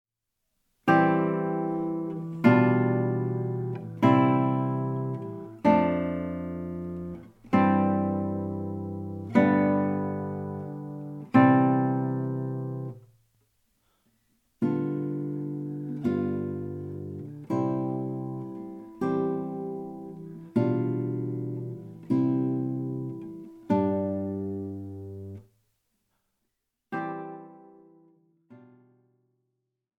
Gitarrenmusik aus Wien von 1800-1856
Besetzung: Gitarre